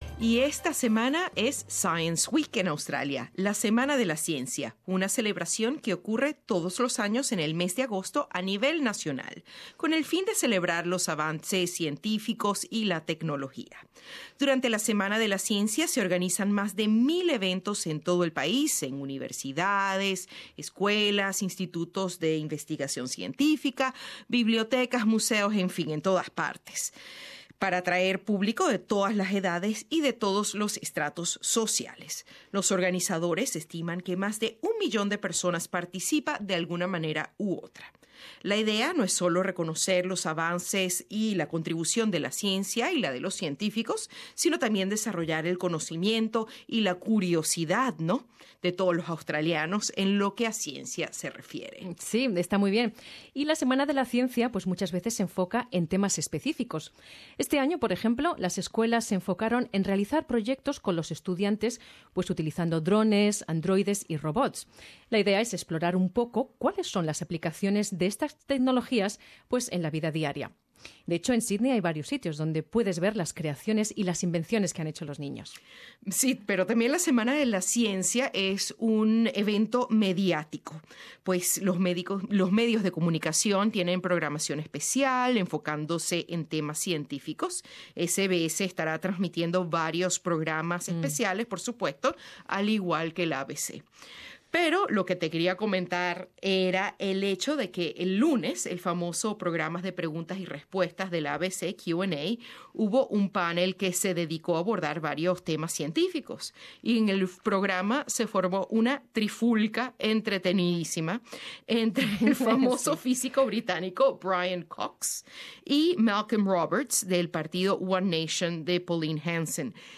Testimonio de un antropólogo que ha vivido de primera mano los efectos en el Pacífico.